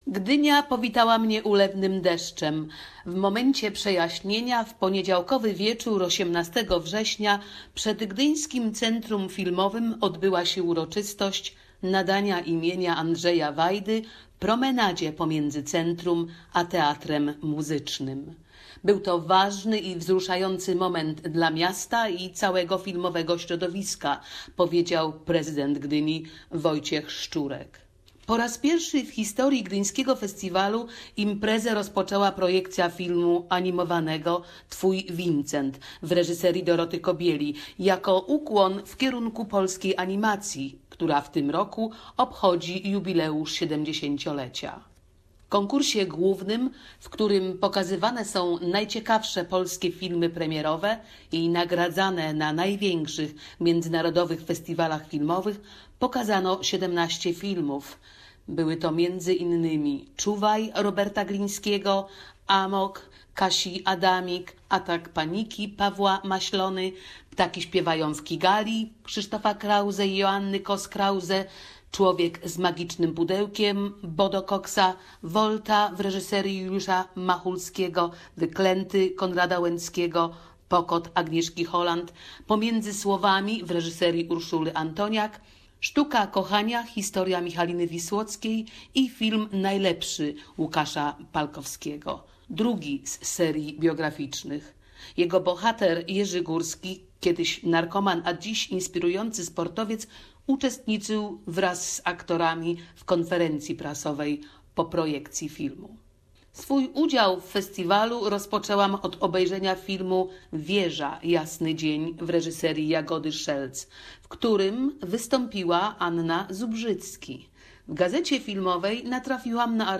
report from Gdynia Film Festival 2017